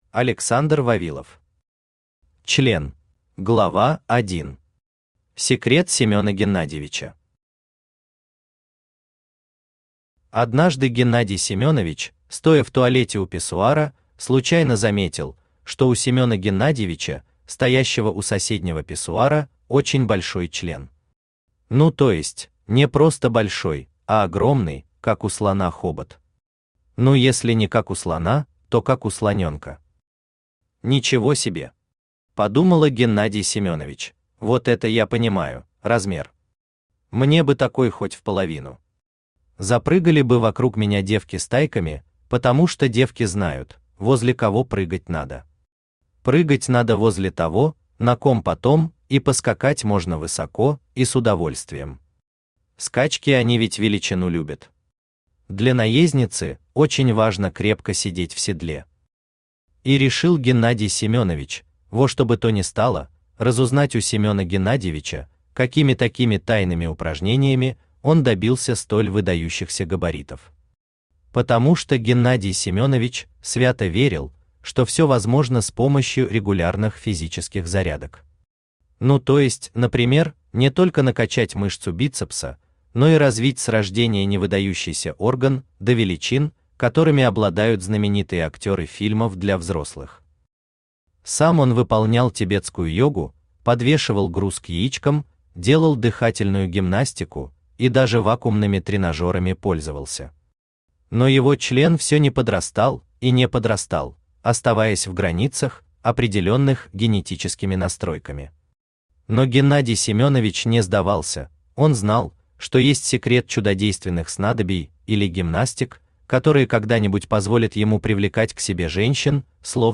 Aудиокнига Член Автор Александр Вавилов Читает аудиокнигу Авточтец ЛитРес.